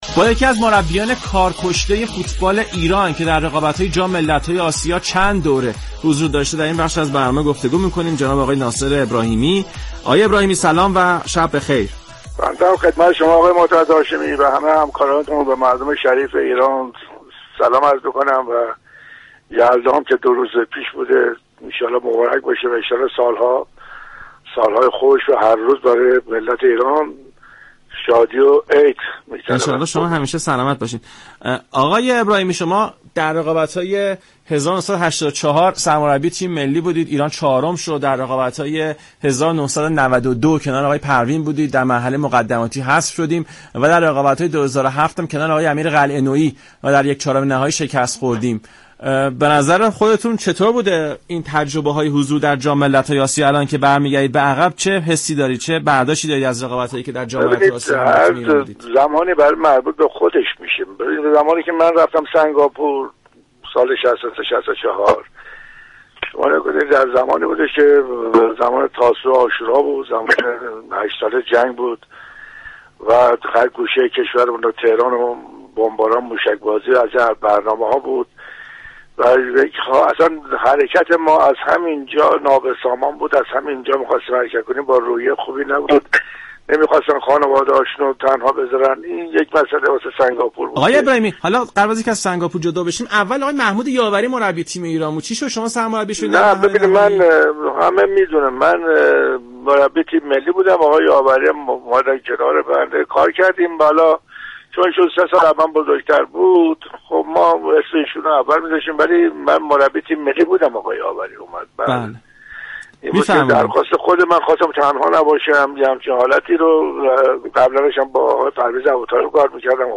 این گفت و گو را می شنویم :